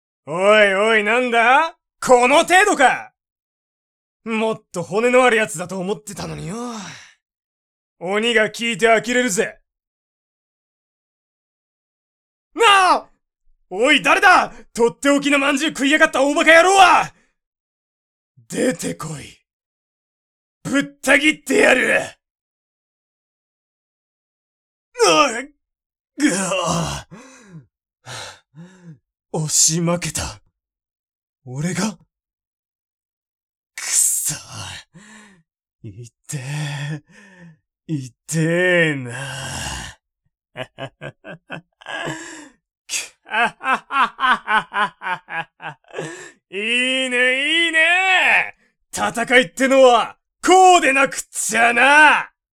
演じていただきました！
性別：男性